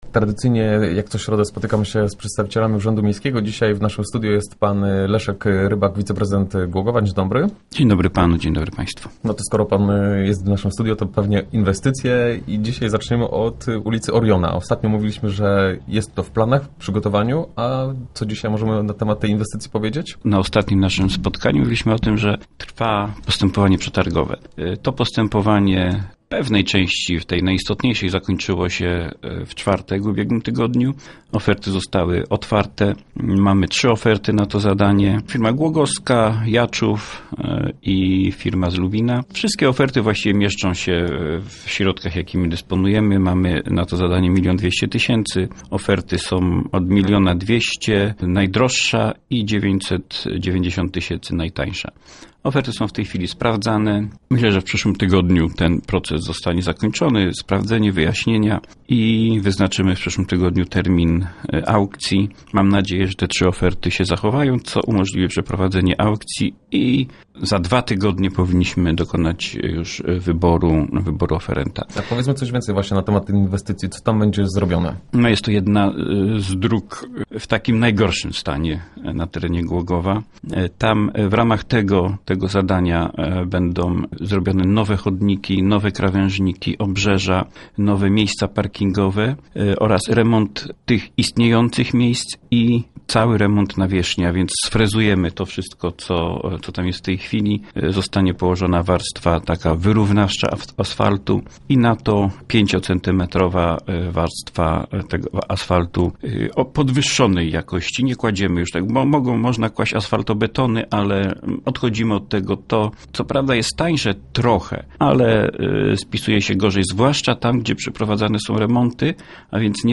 O tym, które zadania prawdopodobnie uda si� zrealizowa� przed czasem, opowiada wiceprezydent Leszek Rybak.